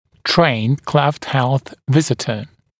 [treɪnd kleft helθ ‘vɪzɪtə][трэйнд клэфт хэлс ‘визитэ]патронажный работник, специально обученный уходу за детьми с расщелиной губы и/или твердого нёба